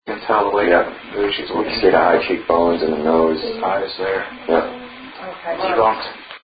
EVP Files
whining.mp3